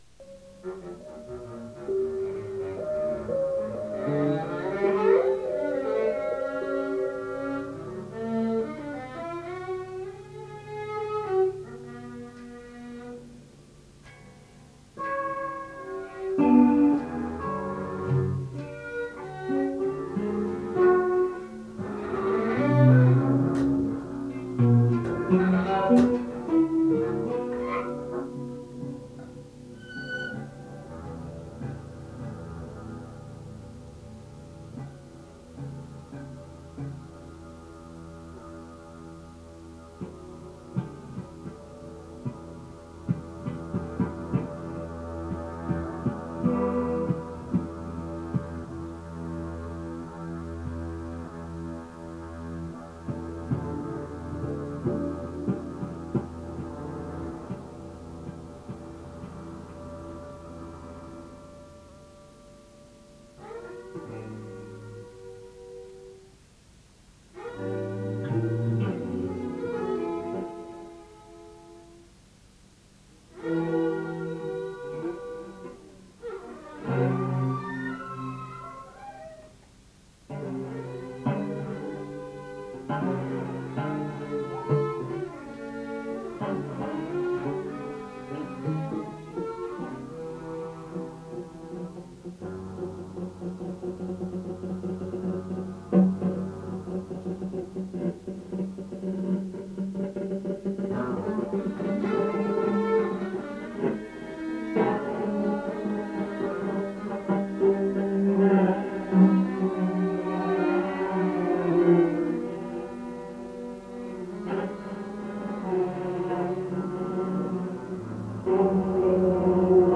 (2)吉沢元治＆今堀恒雄　1990.9.5銀座ケルビーム(27.1MB)